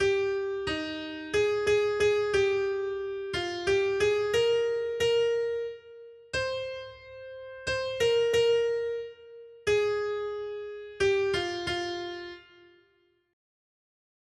Noty Štítky, zpěvníky ol598.pdf responsoriální žalm Žaltář (Olejník) 598 Skrýt akordy R: Ústa spravedlivého mluví moudře. 1.